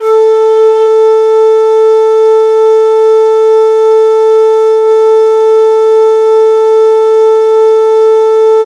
Shakuhachi-flute-440Hz.ogg